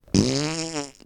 fart1
fart farting farts sound wav wave sound effect free sound royalty free Funny